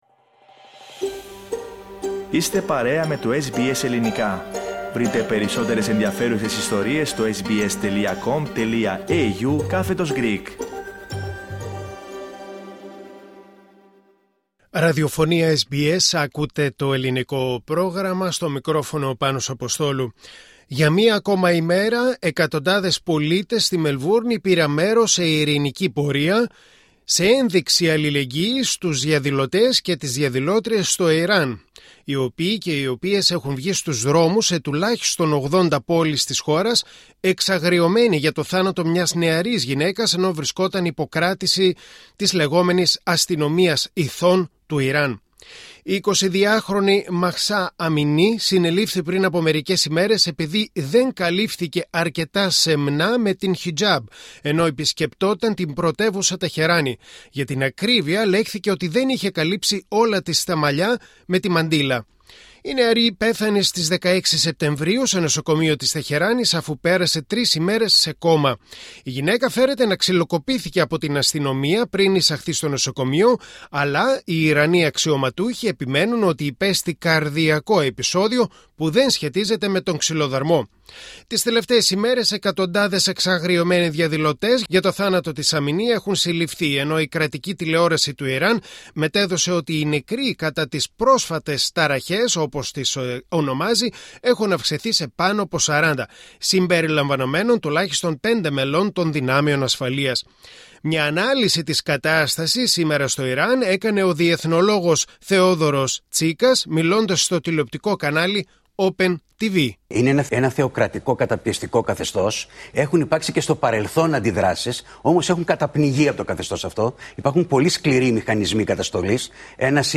Ελληνίδα της Μελβούρνης με οικογένεια από το Ιράν μιλά για την δική της εμπειρία στο Ιράν.